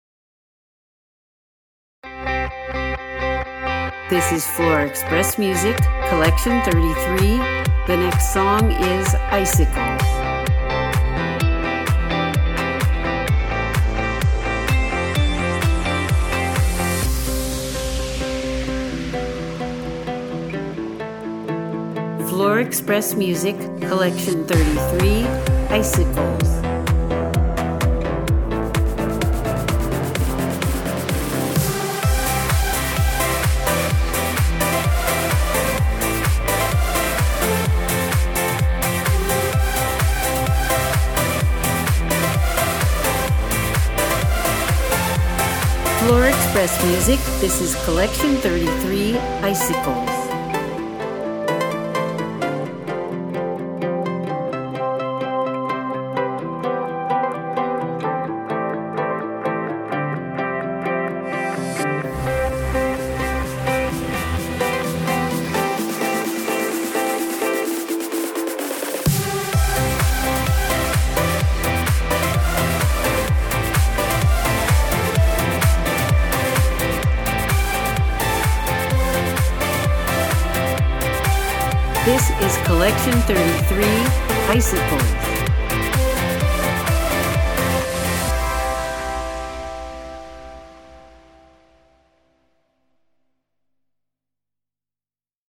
• EDM
• Dance